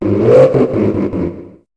Cri de Gouroutan dans Pokémon HOME.